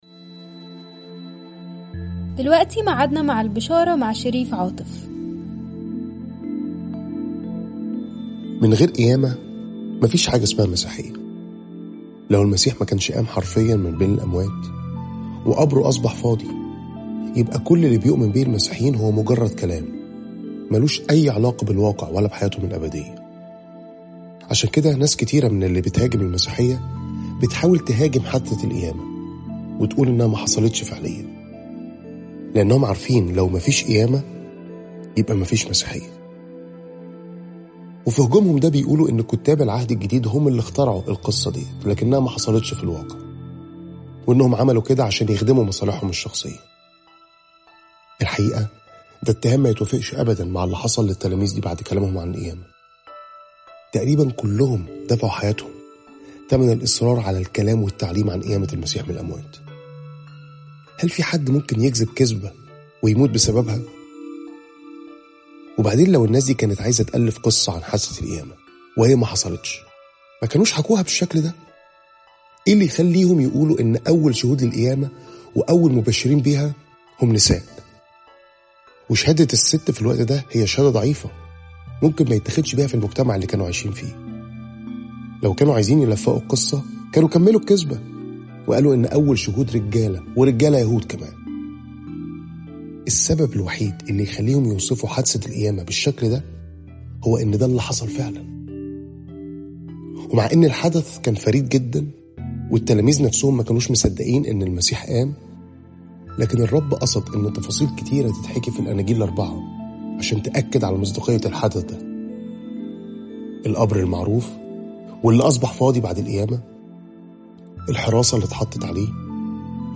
تأملات مُركّزة باللهجة المصرية عن عُمق وروعة صليب المسيح وقيامته